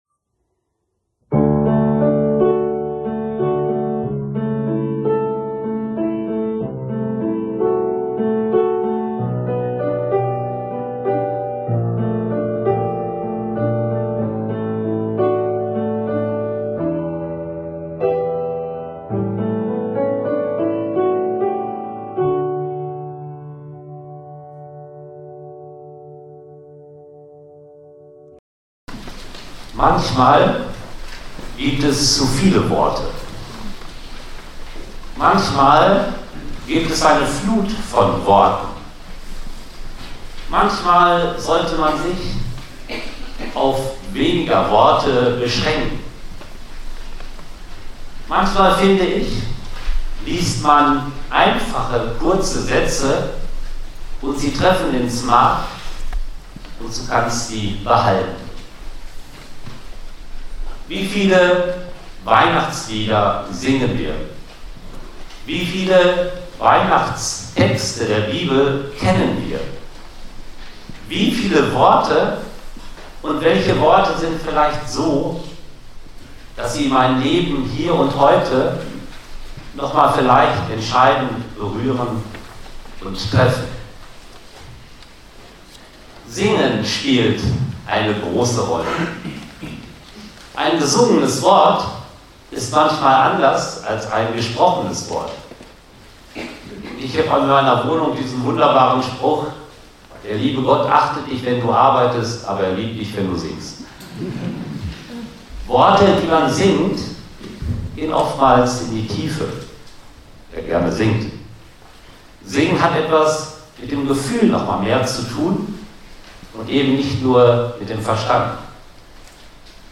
Davon möchte ich Ihnen in der folgenden Predigt erzählen.